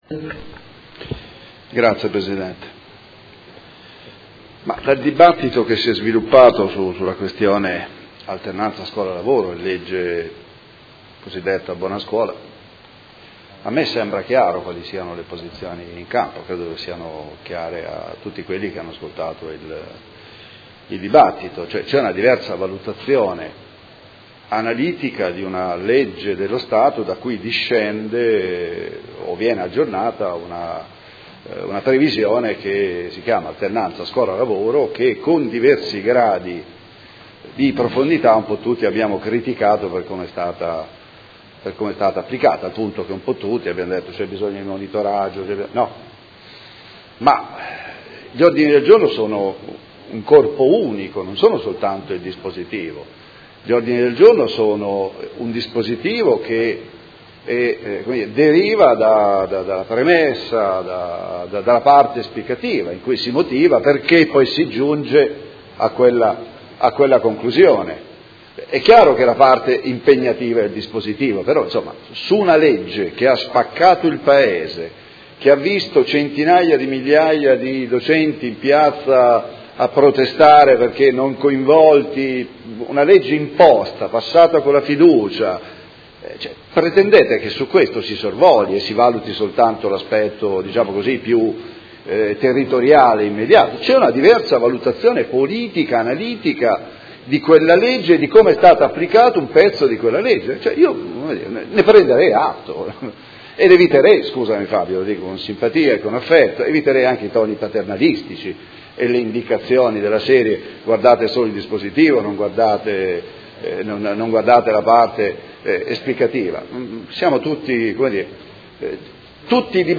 Mozione presentata dai Gruppi Art.1-MDP e Per Me Modena avente per oggetto: Valorizzazione dell’esperienza e monitoraggio dei progetti di alternanza scuola-lavoro nelle scuole di Modena. Dibattito